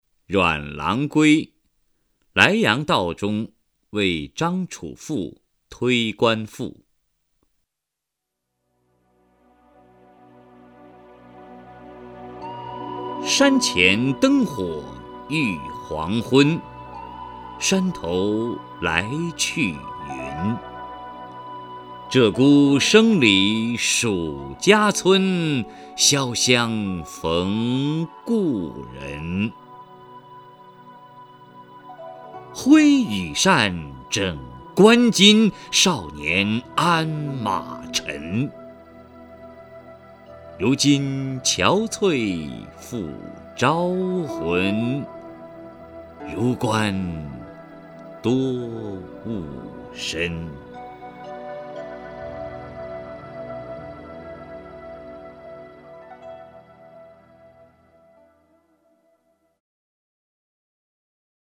名家朗诵欣赏